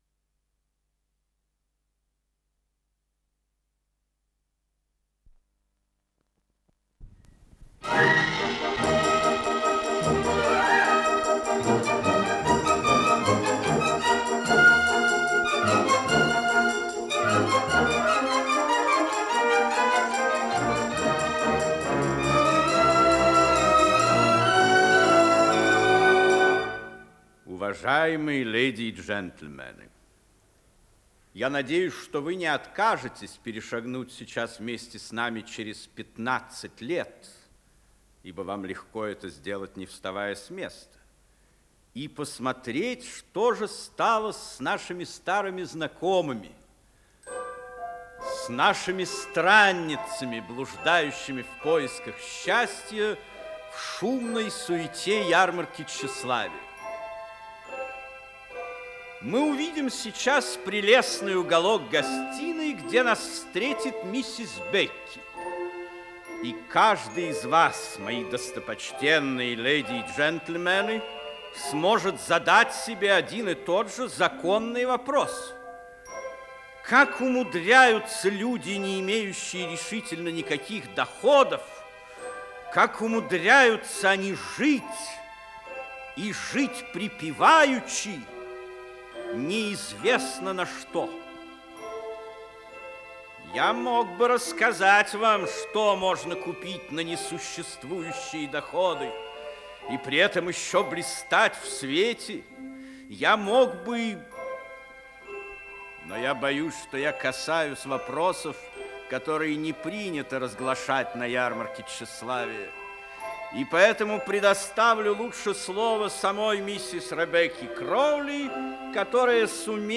Исполнитель: Артисты Государственного академического Малого театра СССР
Спектакль